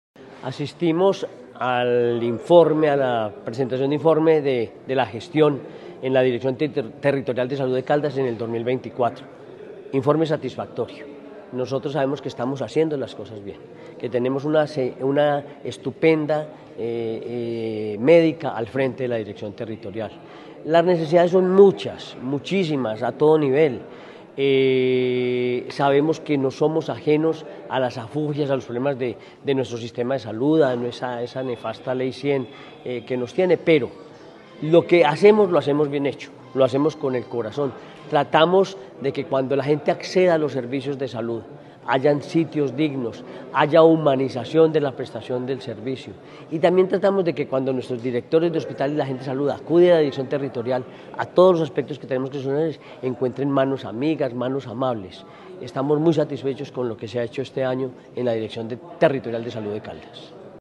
La Dirección Territorial de Salud de Caldas (DTSC) llevó a cabo su Audiencia Pública de Rendición de Cuentas correspondiente a la vigencia 2024, un espacio en el que se destacó el trabajo realizado en pro de la salud y el bienestar de los caldenses.
Henry Gutiérrez Ángel, gobernador de Caldas.
AUDIO-HENRY-GUTIERREZ-ANGEL-GOBERNADOR-DE-CALDAS-TEMA-R.C.mp3